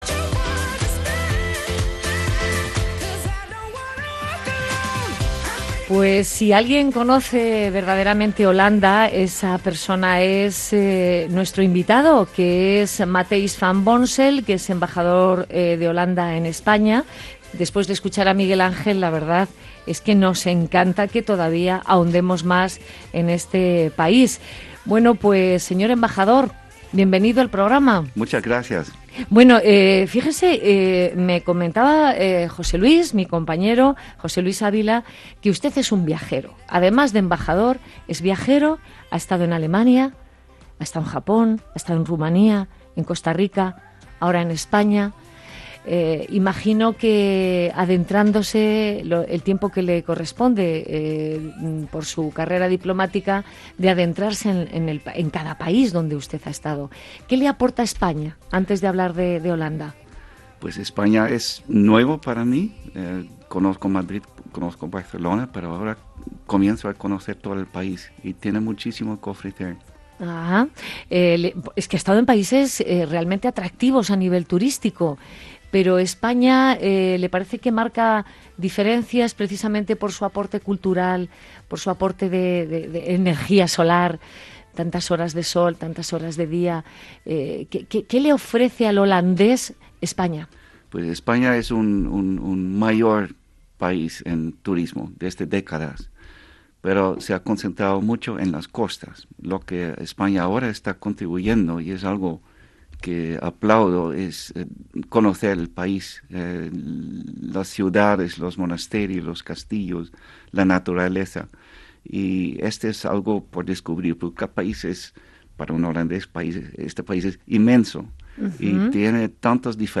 En “Vivir viajar” tuvimos el honor de recibir en nuestros estudios de Capital Radio al embajador de los Países Bajos en España.
Archivos Adjuntos Entrevistamos al embajador de los Países Bajos en España.